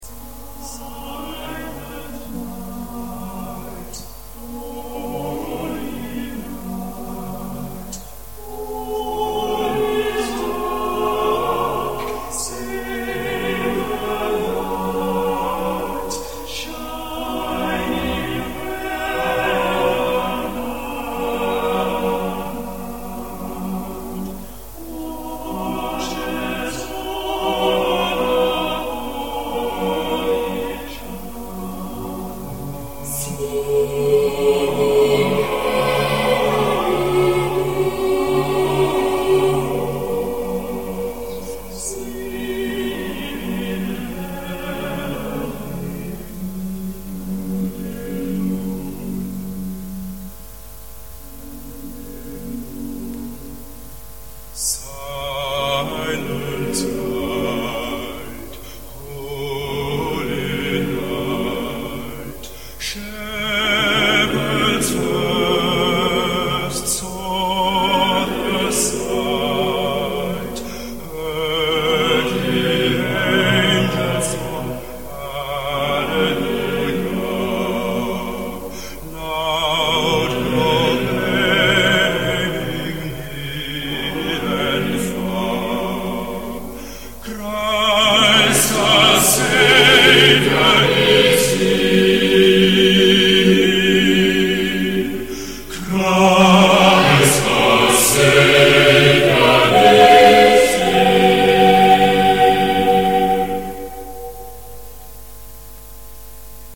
Я предлагаю вам прослушать Международный рождественский гимн "Тихая ночь" в исполнении смешанного хора, который возвещает о рождении Иисуса Христа….Вслушайтесь в мелодию гимна и прочувствуйте торжество этого праздника.!!!